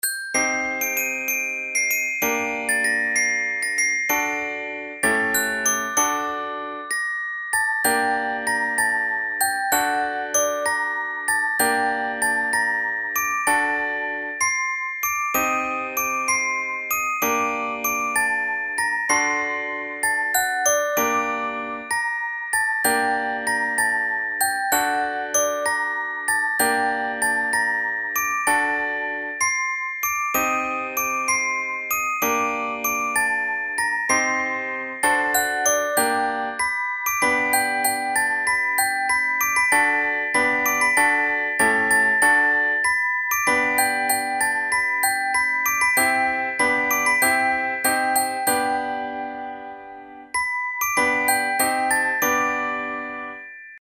Vous écoutez une musique traditionnelle du comté de Nice L’Ouferta de Calèna (L’Offerte de Noël).